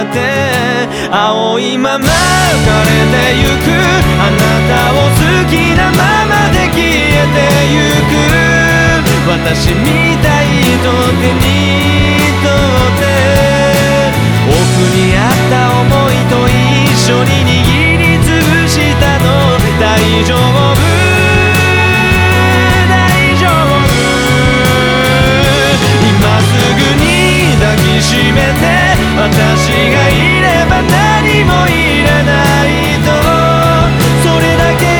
Жанр: Поп музыка
J-Pop